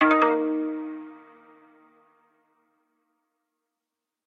reminder.ogg